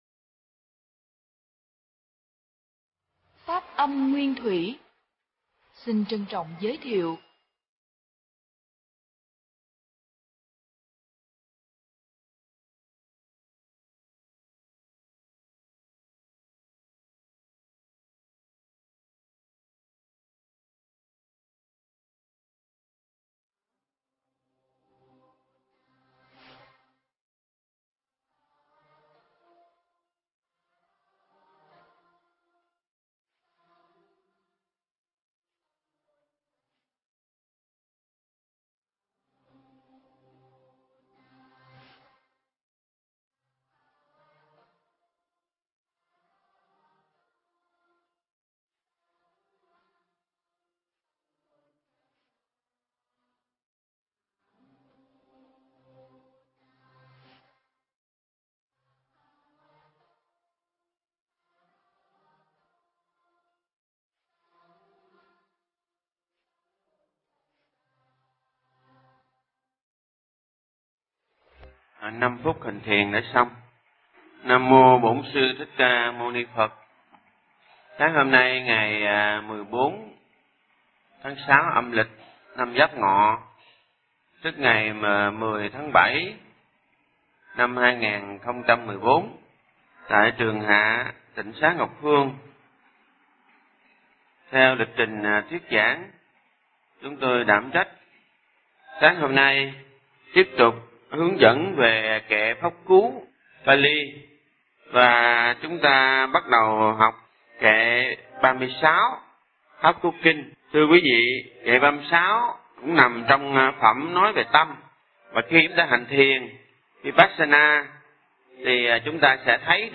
Nghe Mp3 thuyết pháp Kinh Pháp Cú 36